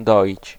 Ääntäminen
UK : IPA : [mɪɫk] US : IPA : [mɪɫk] UK Tuntematon aksentti: IPA : /ˈmɪlk/